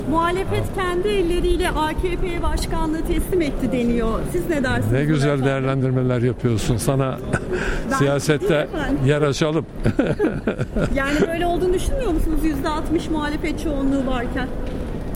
Deniz Baykal'ın Açıklaması